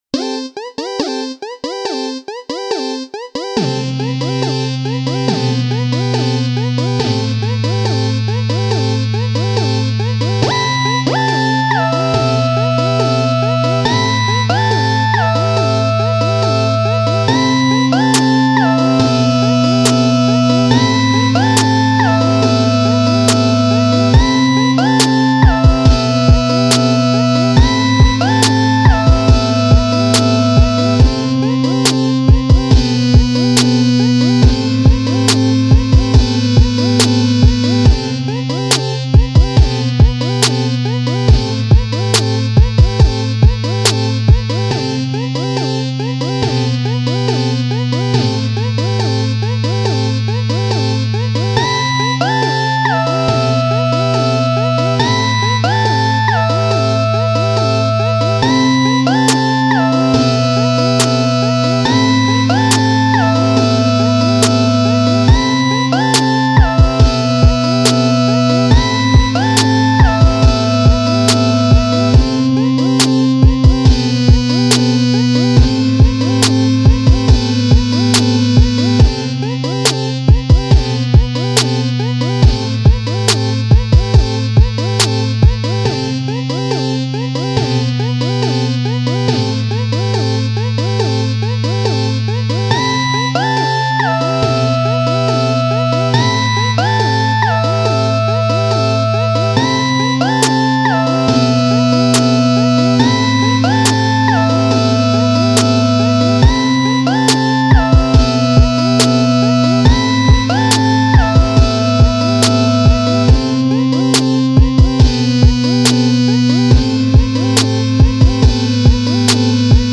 ORIGINAL INSTRUMENTALS